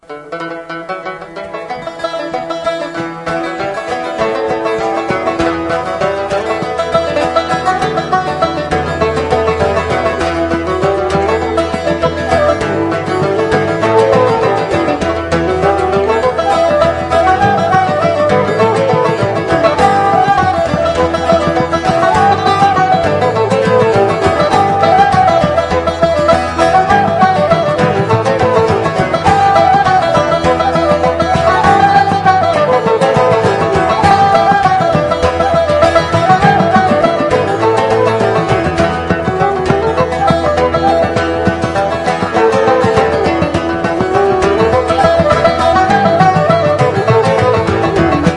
pražská skupina, hrající irskou lidovou hudbu.